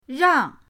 rang4.mp3